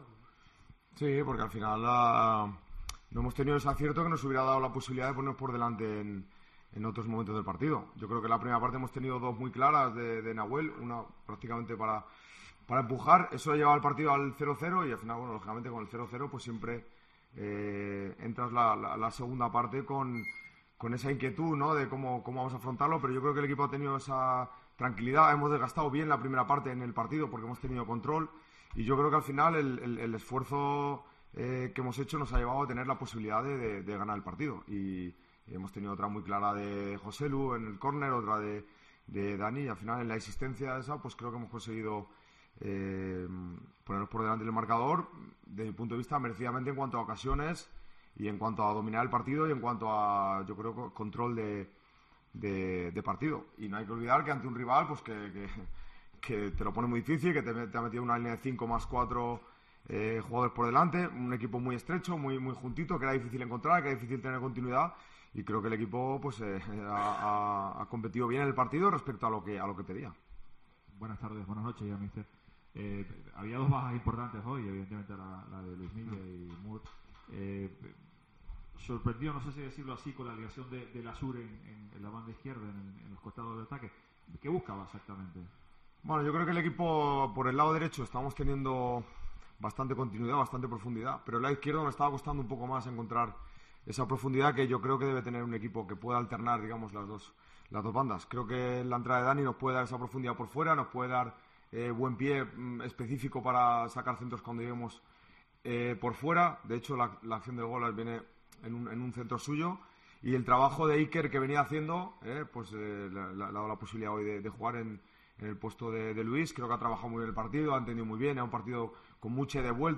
AUDIO: Escucha aquí las palabras de Rubén Baraja, entrenadror del Tenerife, tras ganar 1-0 a la Deportiva Ponferradina